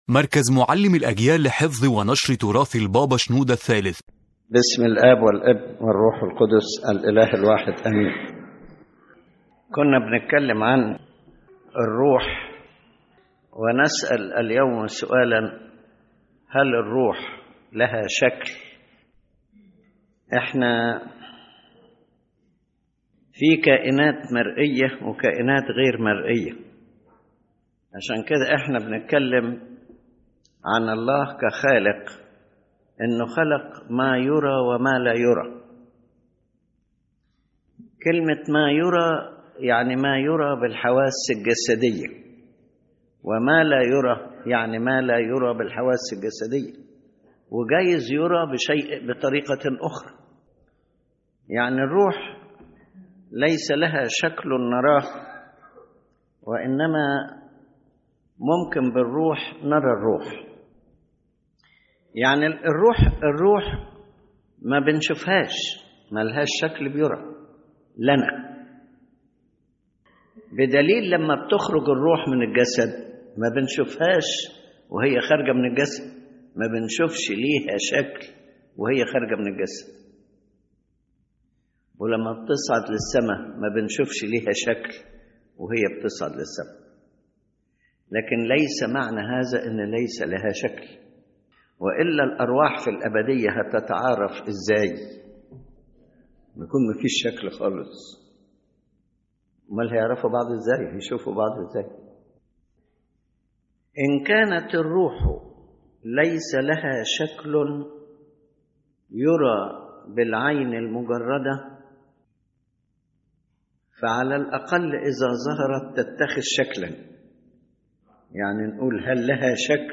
• His Holiness Pope Shenouda III explains that God created visible and invisible beings, and what is not seen by physical senses does not mean it does not exist.